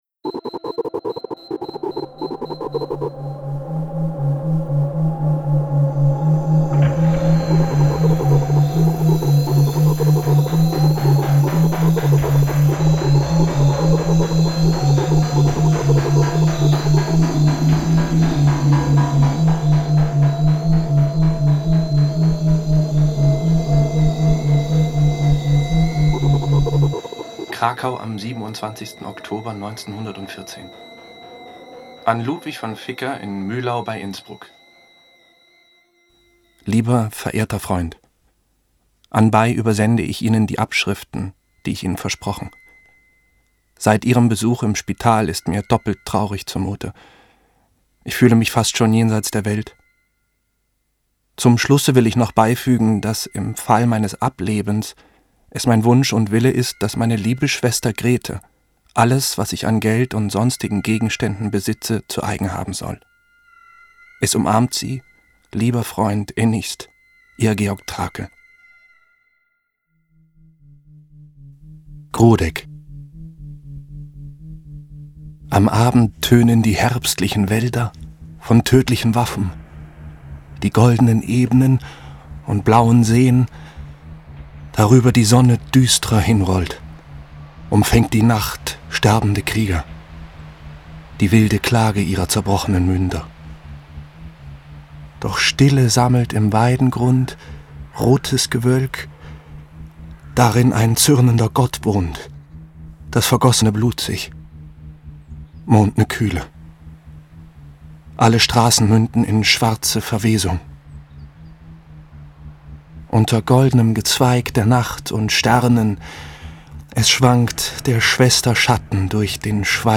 Lesung mit Tobias Moretti, Max von der Groeben, Albrecht Schuch, Paula Beer u.v.a. (1 mp3-CD)
Rausch, Schmerz, Sucht – Georg Trakls Leben war geprägt von Extremzuständen und existenziellen Krisen. Dennoch erschuf der österreichische Lyriker und Expressionist in seinen gerade einmal 27 Jahren ein außergewöhnliches und beeindruckendes Werk, das bis heute nachhallt. 32 Schauspieler:innen, darunter Tobias Moretti, Paula Beer, Albrecht Schuch, Hannah Herzsprung, Max von der Groeben, Karoline Schuch u. v. a., interpretieren Trakls Gedichte auf vielstimmige Weise und erzeugen so, eingebettet in ein extra für diese Produktion geschaffenes Soundkonzept, einen ganz eigenen »Trakl-Sound«.
Ungekürzte Lesung mit Tobias Moretti, Max von der Groeben, Albrecht Schuch, Paula Beer